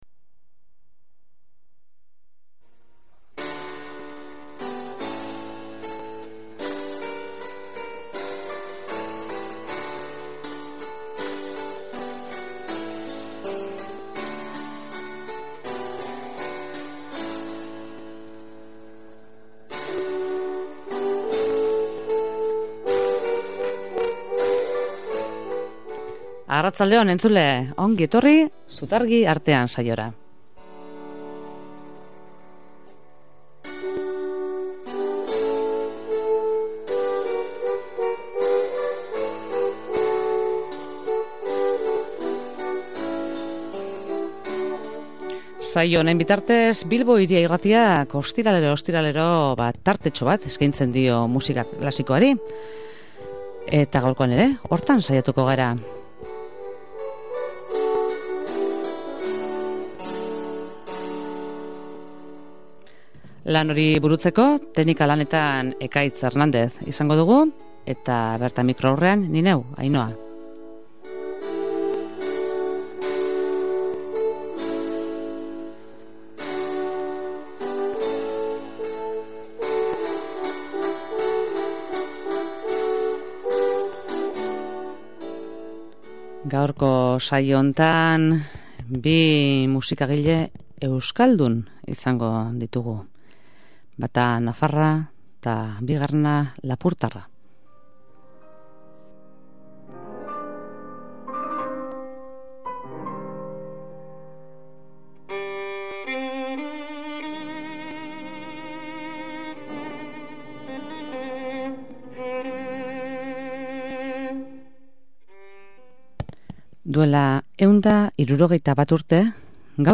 Horrezaz gainera, piezen artean zipriztindurik, bien bizitzari buruzko zertzelada solteak ere eskaintzen ditu saio honek.